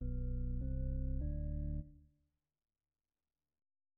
\new Staff { \clef bass \set Staff.midiInstrument = "drawbar organ" b, c d }
orgonahangja két oktávval magasabb, mint kellene.